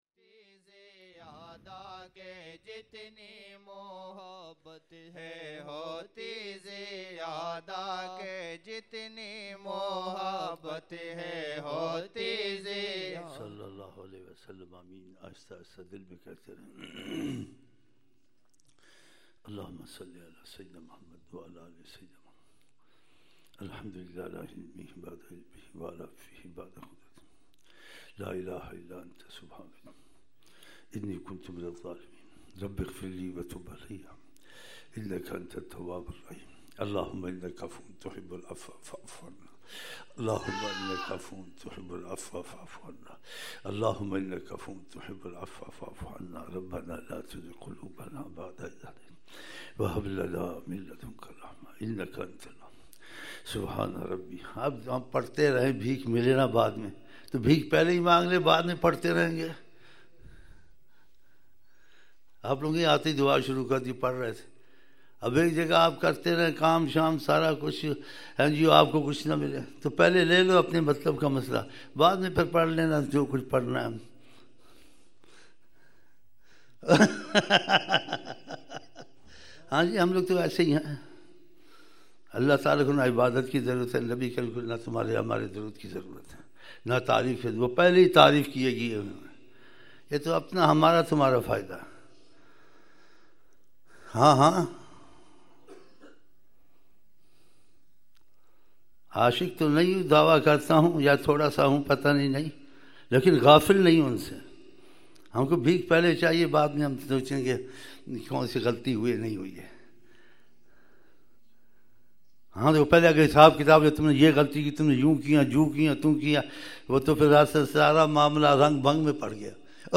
02 January 2004 - Jumma Mehfil (10th Dhul Qadah, 1424h)